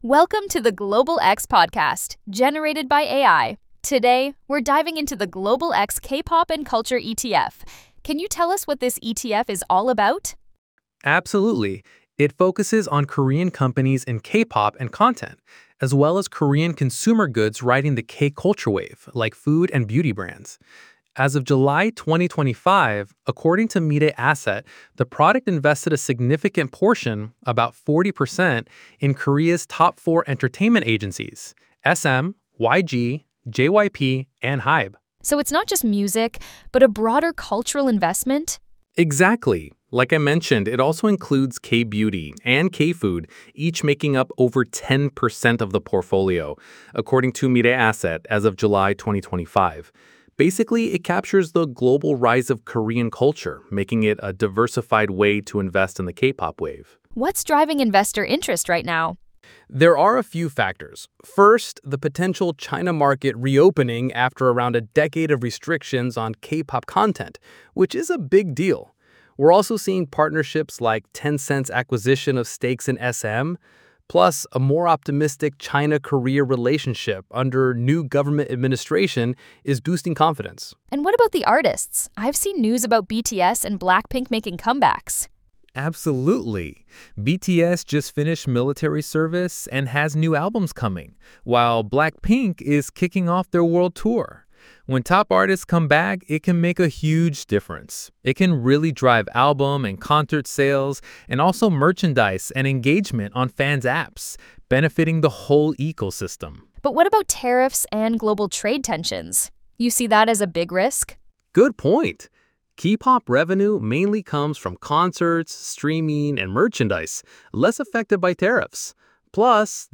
Category : AI Explainer